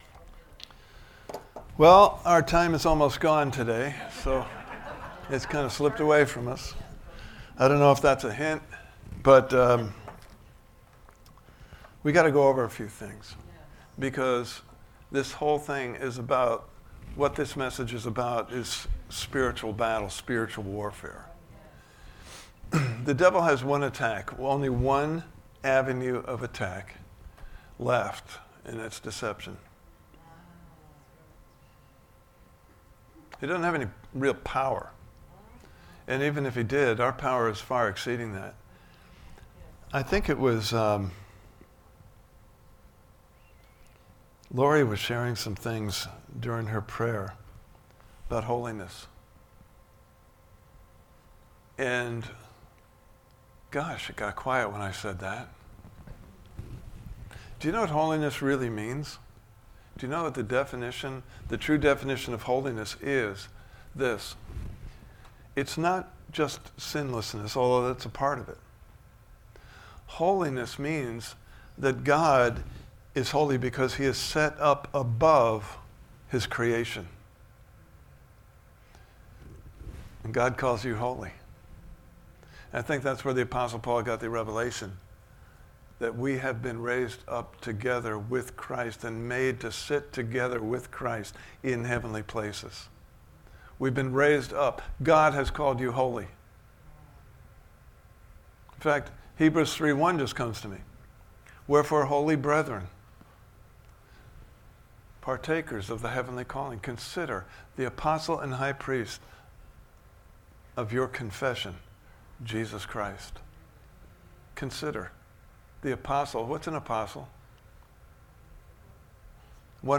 Standing on a Firm Foundation Service Type: Sunday Morning Service « Part 3